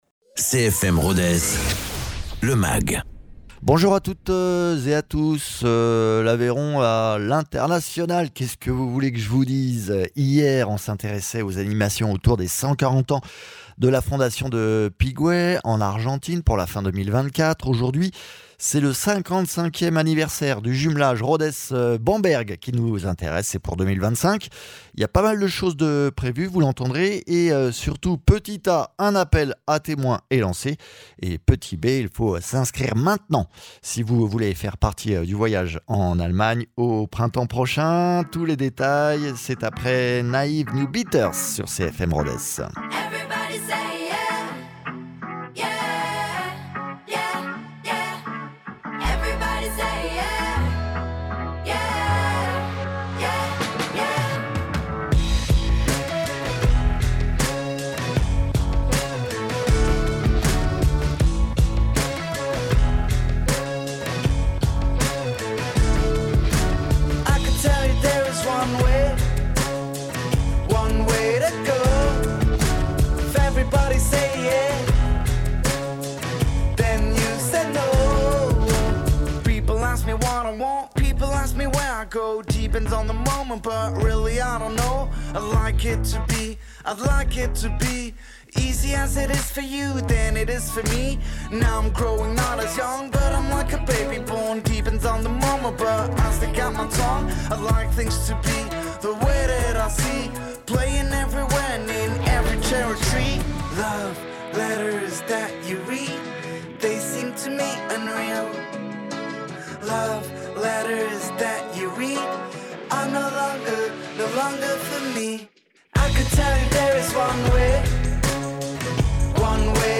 Invité(s) : Régine Taussat, adjointe à la mairie de Rodez